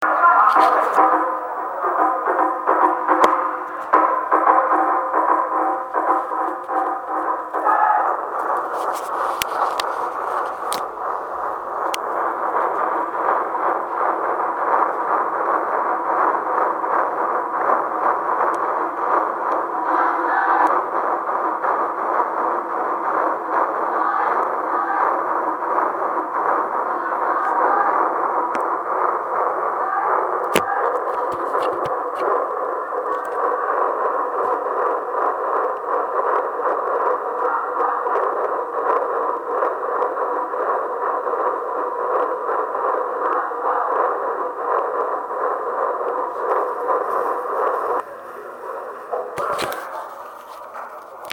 会の初めに紫野太鼓が勇壮に奏じられた。
A gromwell field drum is played in courageousness at the beginning of a meeting.